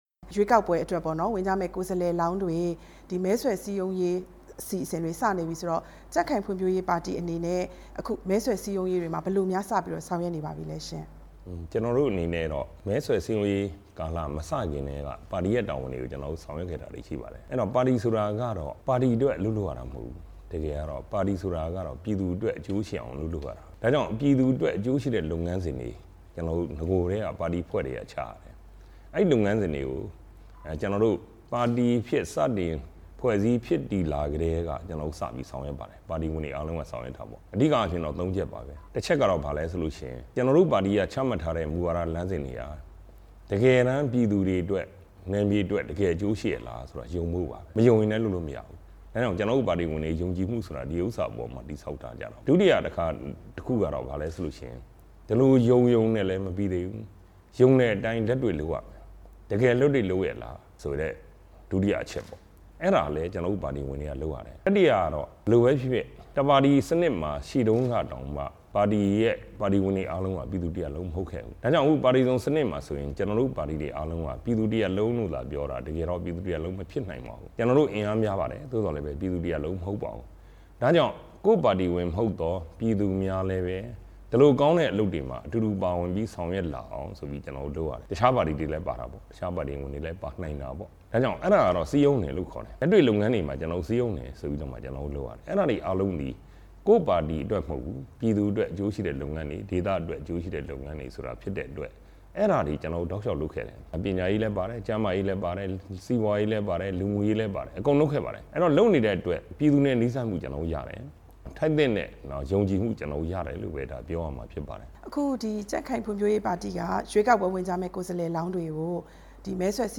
ပြည်ခိုင်ဖြိုးပါတီ ပူးတွဲဥက္ကဌ ဦးဌေးဦးနဲ့ တွေ့ဆုံမေးမြန်းချက်(ပထမပိုင်း)